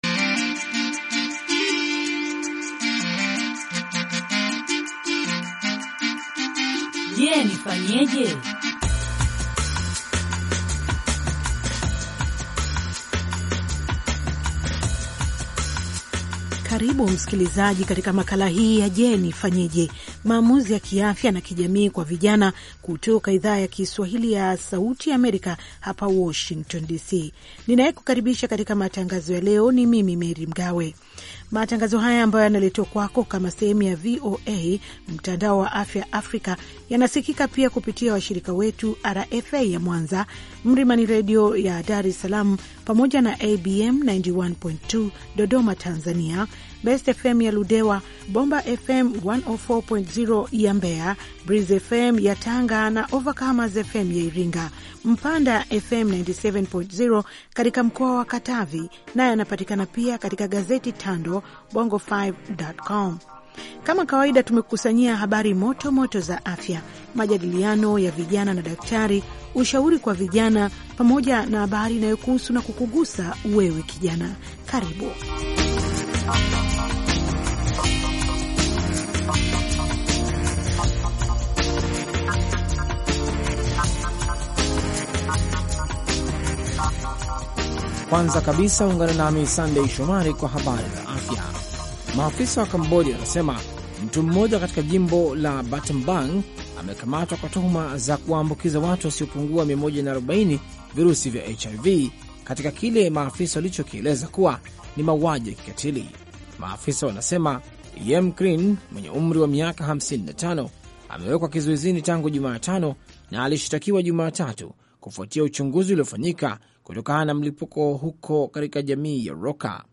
Je Nifanyeje? Maamuzi ya Afya na Kijamii kwa Vijana - Kipindi cha dakika 30 kinacholenga vijana kwa kufuatulia maisha ya vijana, hasa wasichana, kuwasaidia kufanya maamuzi mazuri ya kiafya na kijamii ambayo yanaweza kuwa na maana katika maisha yao milele. Kipindi hiki kina sehemu ya habari za afya, majadiliano, na maswali na majibu kwa madaktari na wataalam wengine.